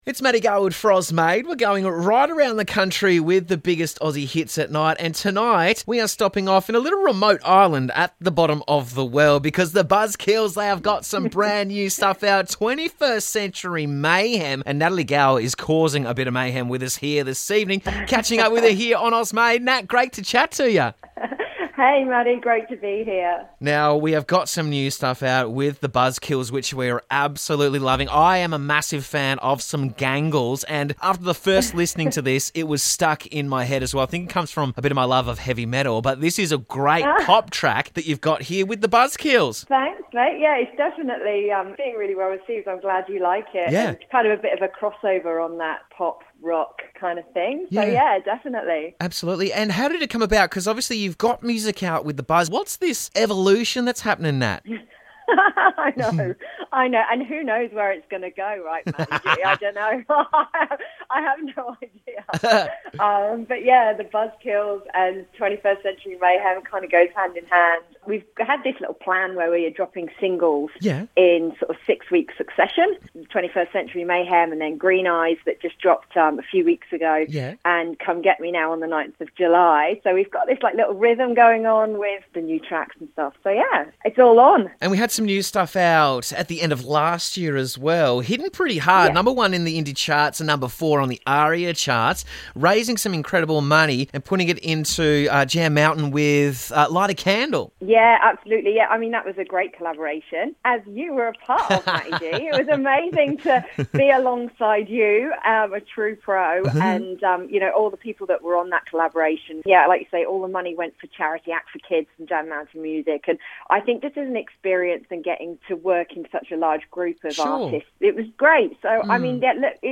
captivating vocalist and songwriter
think k-pop meets a drug dealer in a dark laneway.